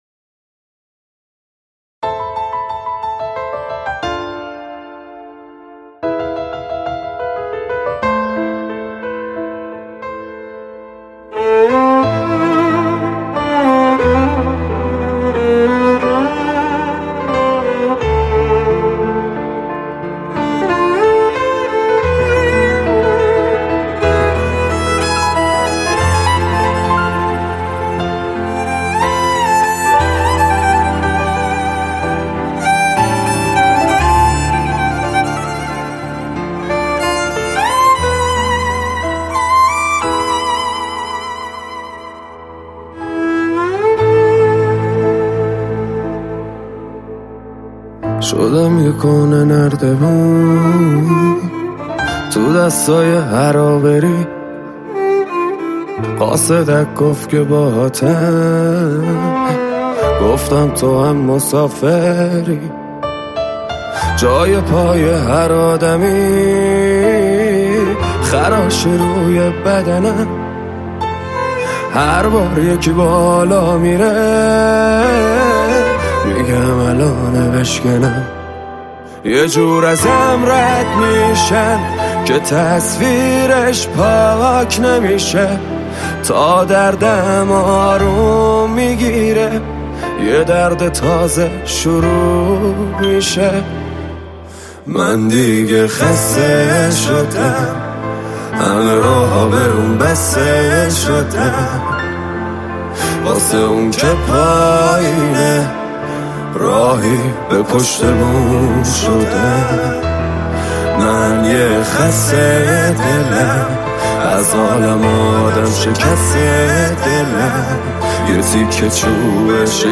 دانلود آهنگ غمگین جدید